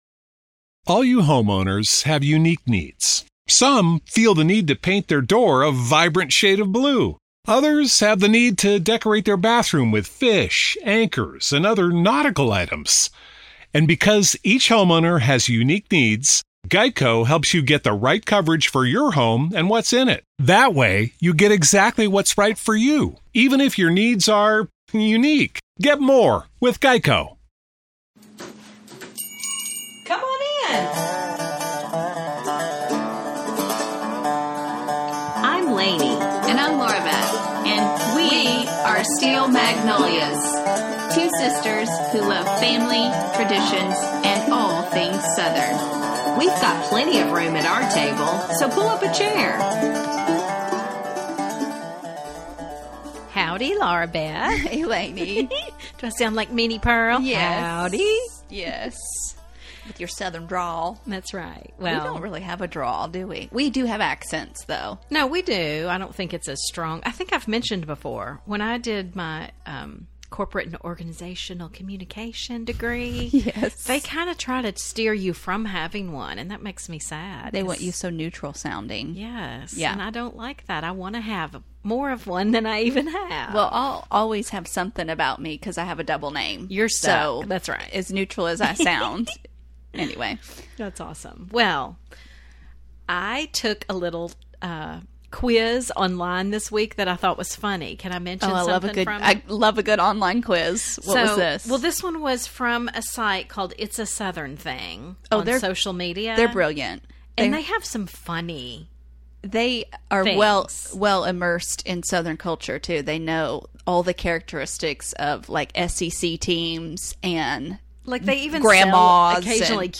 The Natchez Trace | Steel Magnolias - uplifting conversations about life in the South